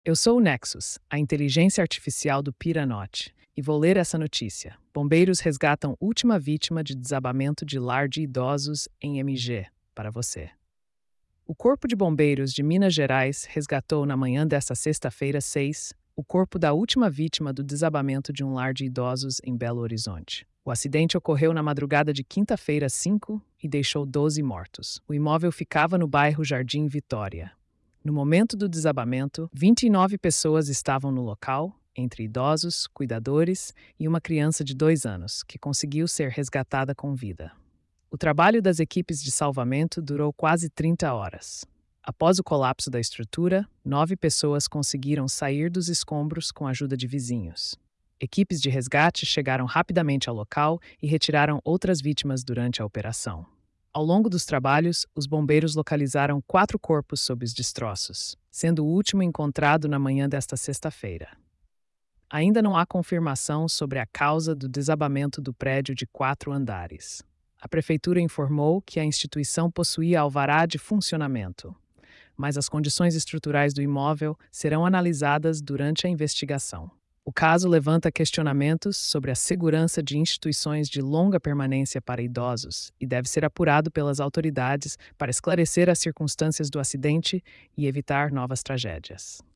Nexus AI · Ouca esta noticia · 1:53